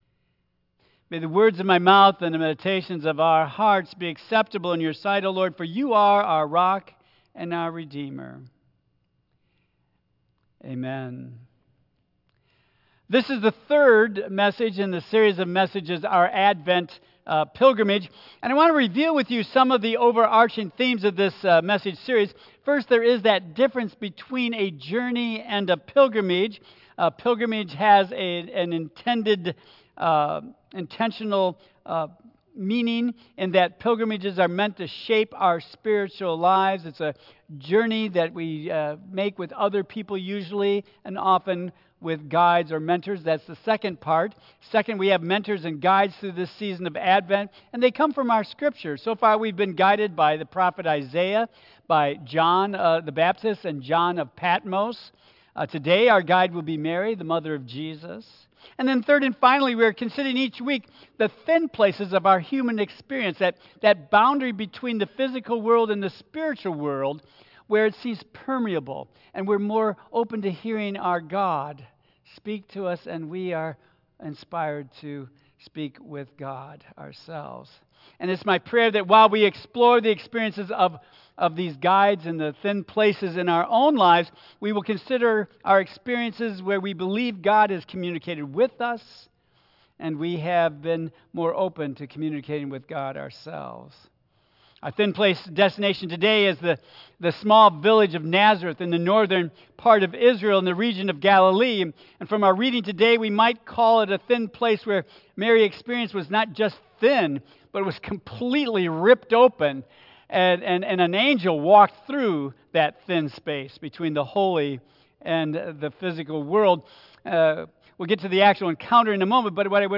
Tagged with 2025 , Advent , Michigan , Sermon , Waterford Central United Methodist Church , Worship